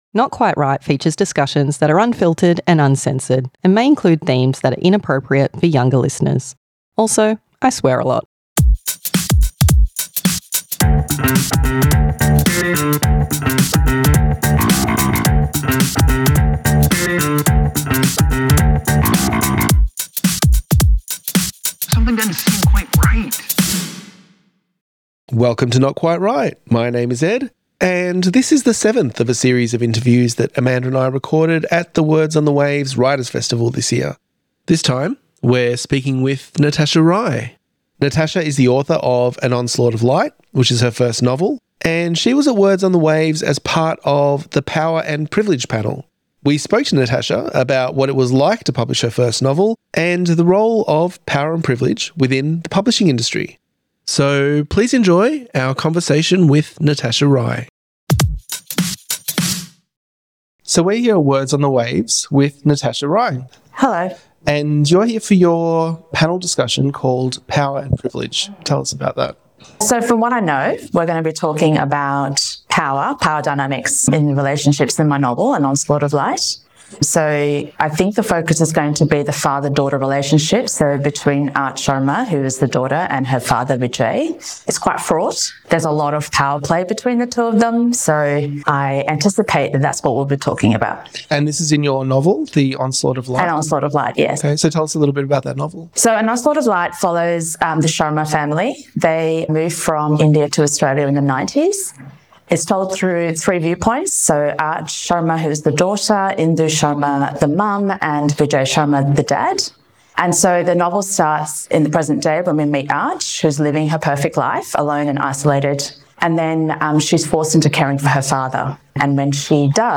Not Quite Write Interviews